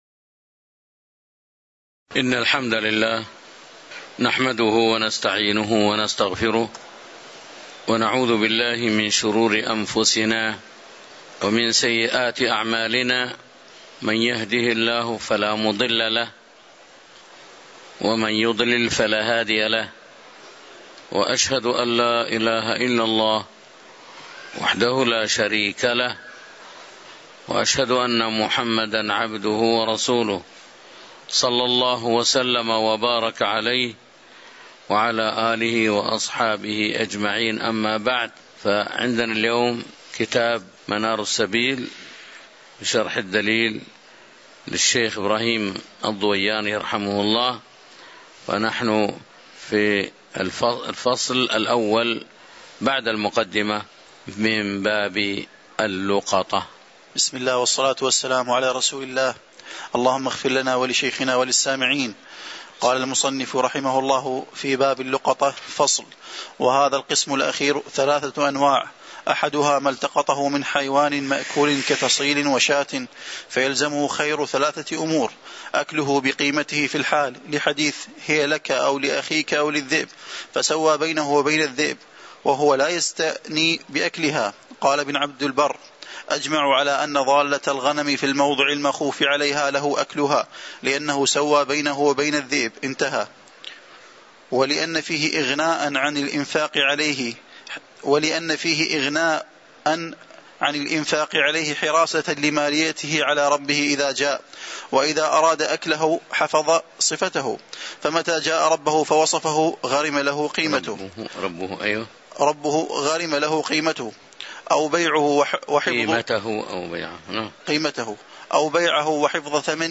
تاريخ النشر ١٨ محرم ١٤٤٤ هـ المكان: المسجد النبوي الشيخ